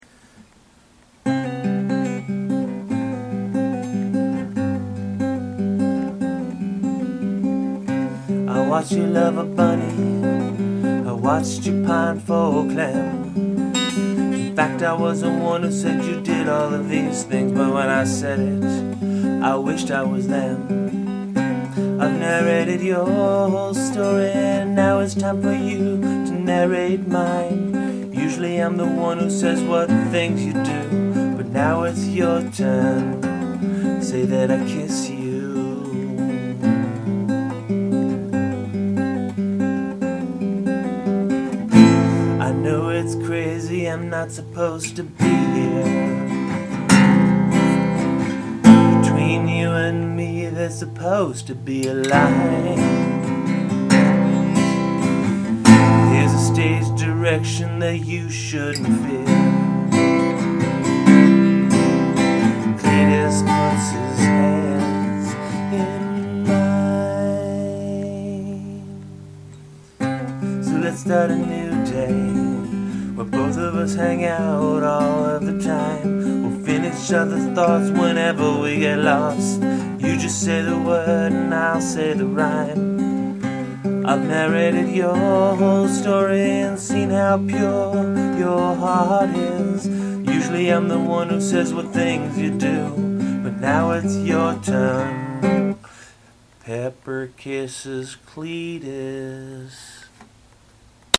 demo track of the above song